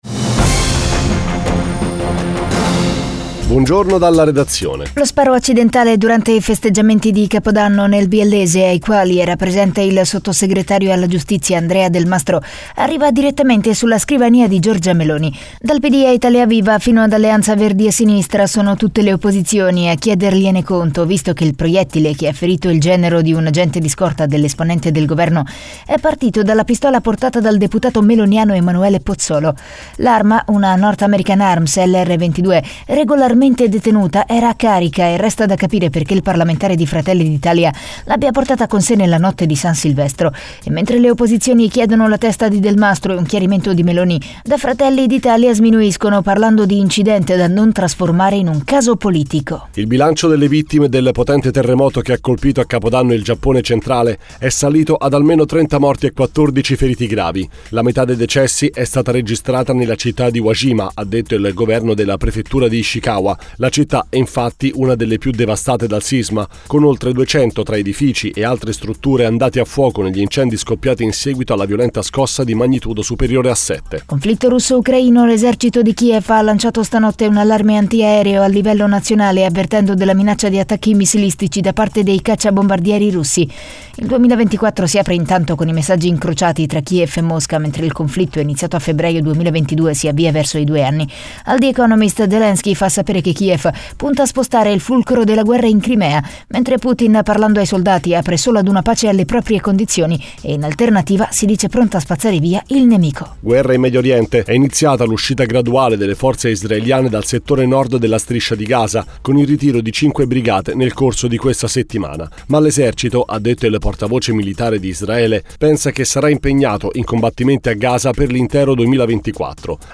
DIRETTA RADIO
GIORNALE RADIO NAZIONALE, EDIZIONE DELLE SETTE
giornale07.mp3